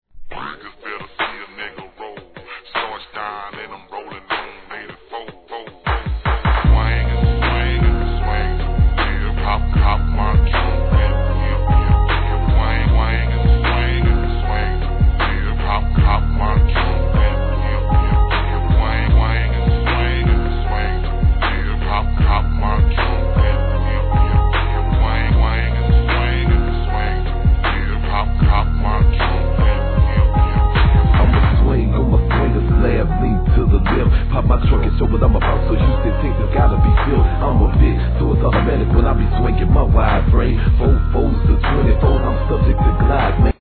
G-RAP/WEST COAST/SOUTH
大爆音で聴いたらたまらないベースラインに、綺麗なメロディーにキキラキラ上音が心地よい!!